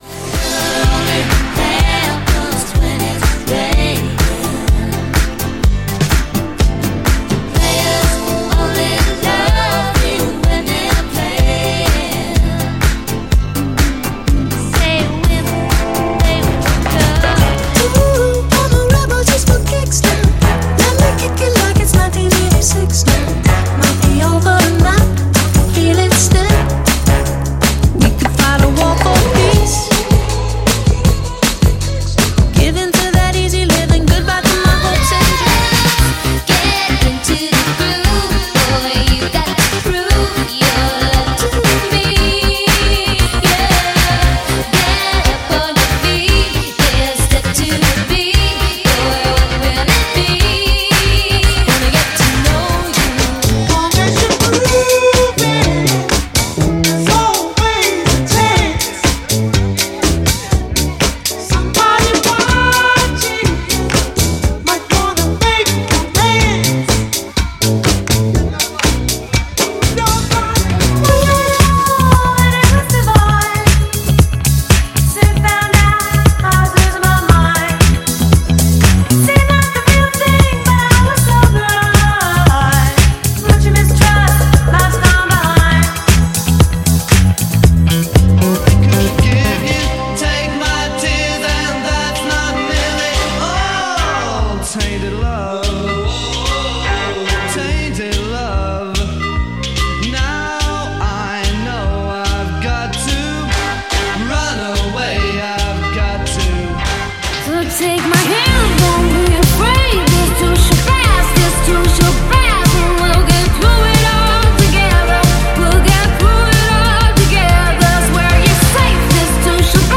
All Time Classics